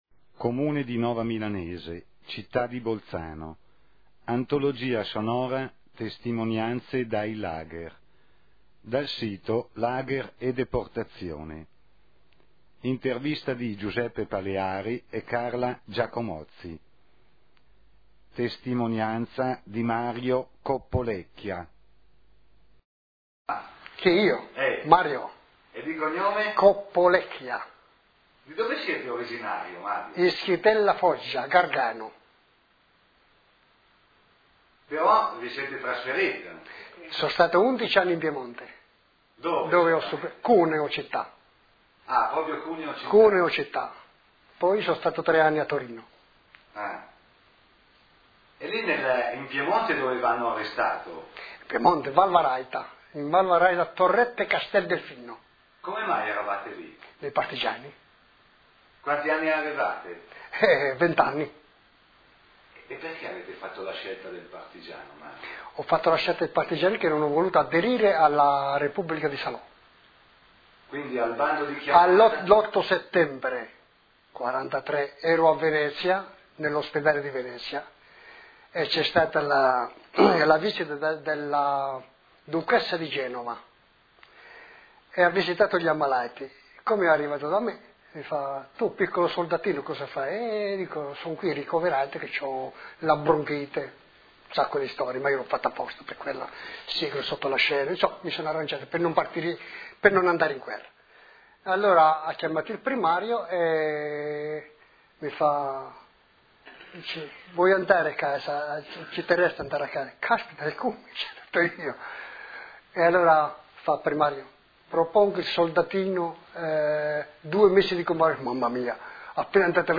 Intervista del